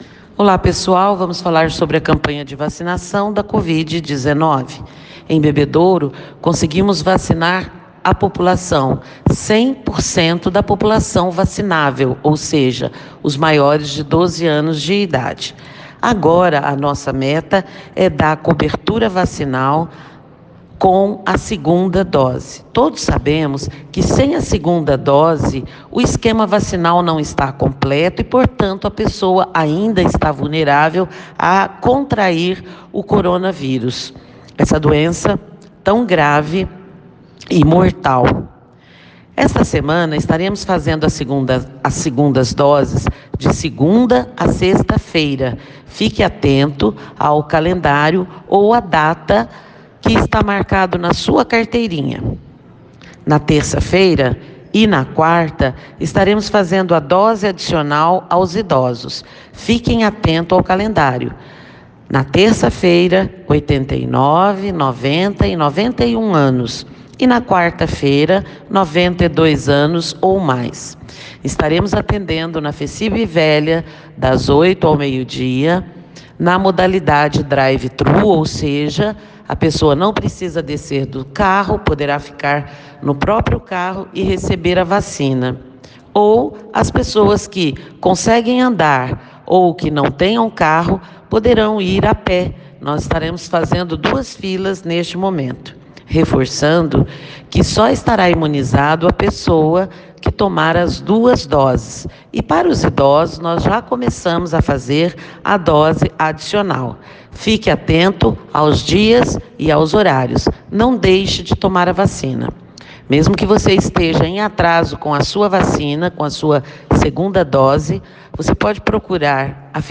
Clique aqui e ouça o áudio da secretária de Saúde, Silvéria Maria Peixoto Laredo